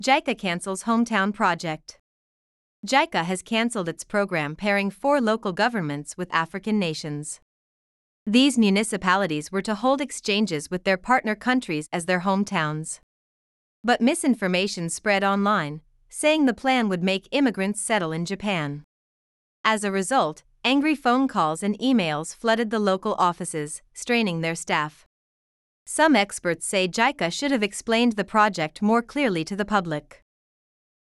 【ナチュラルスピード】